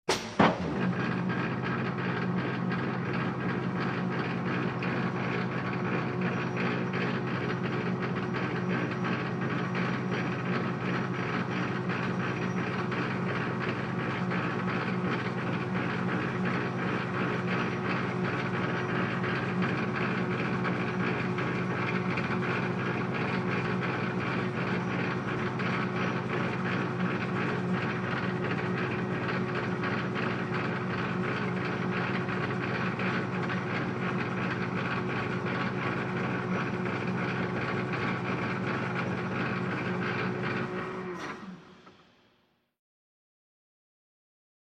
Звук поднятия тяжести краном